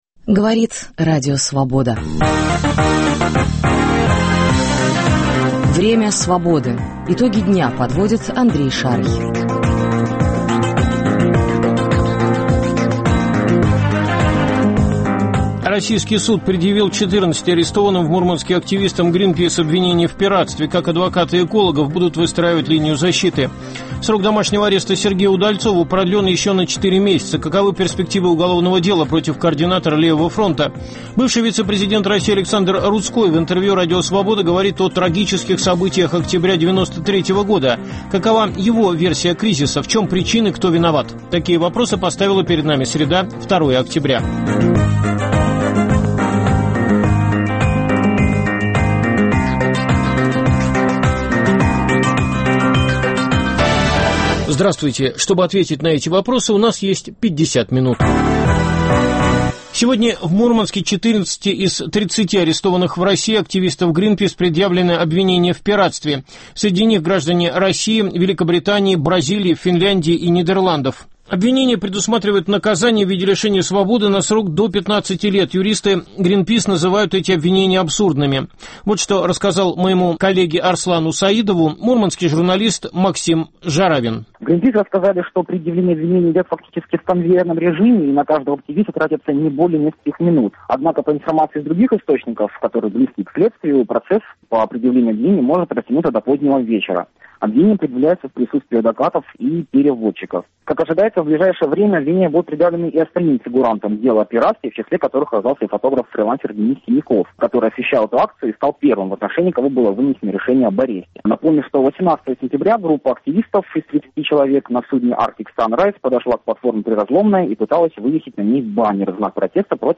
Бывший вице-президент России Александр Руцкой в интервью РС говорит о трагических событиях октября 1993 года.